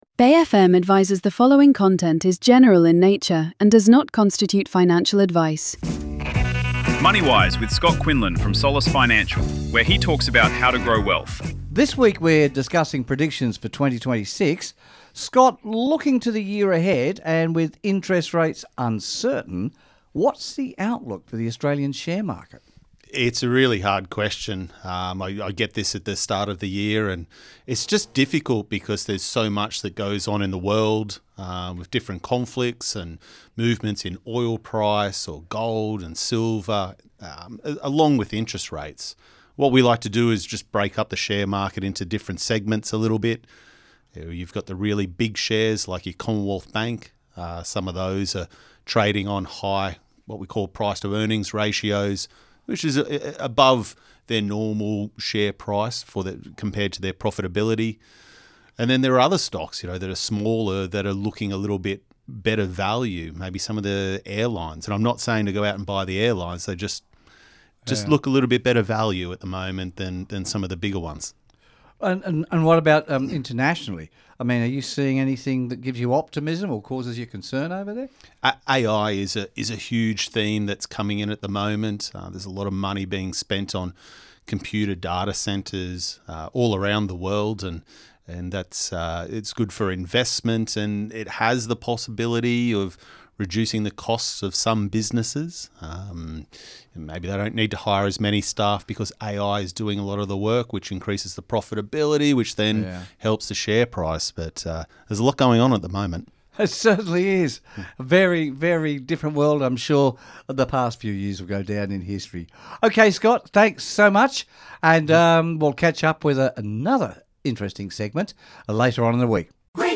radio segment